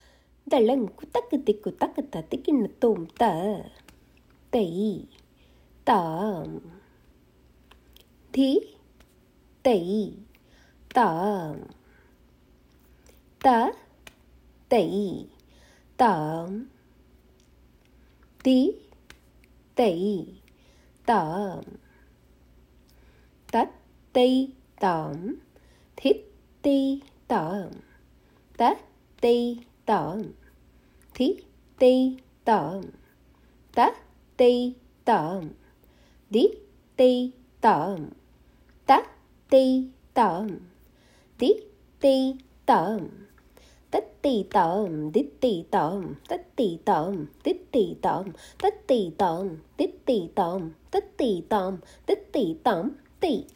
The following is the sollukettu for sutral adavu. The Bols are Tat Tai Tam Dhit Tai Tam.